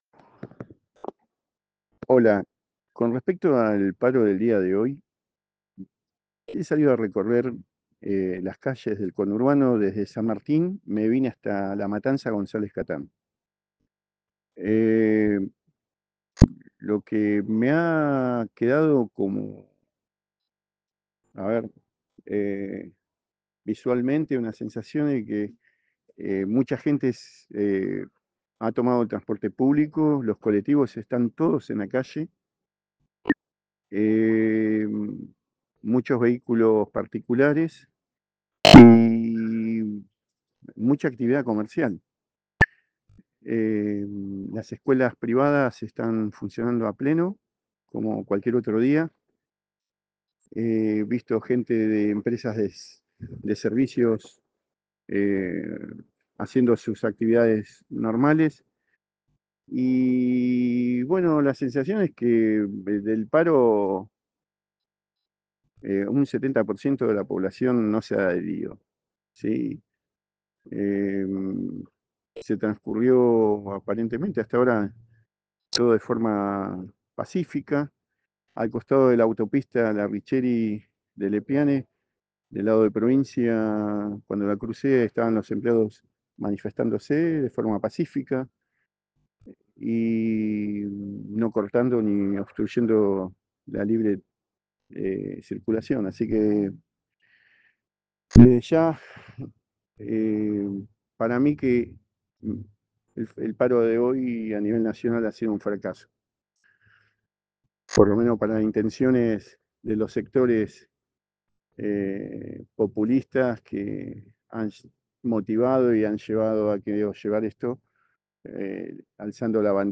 Desde Argentina Política, conversamos con diferentes actores afines al gobierno Nacional.